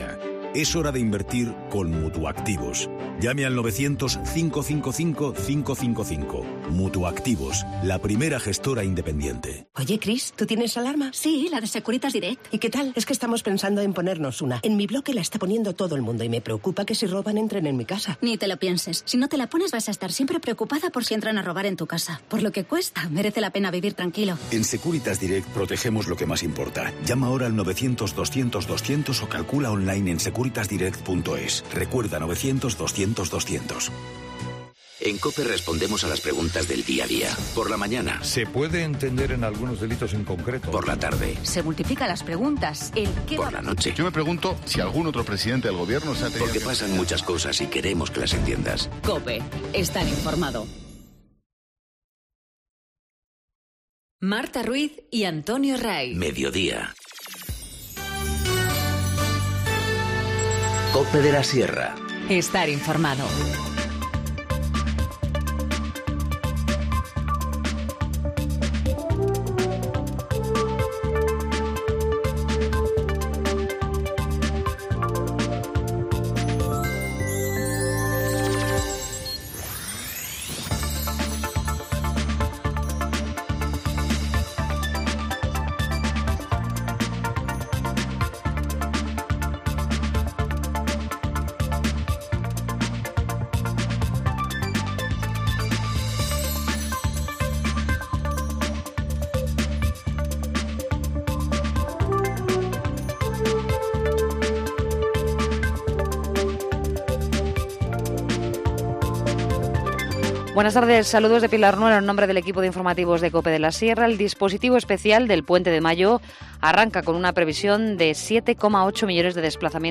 Informativo Mediodía 30 abril 14:20h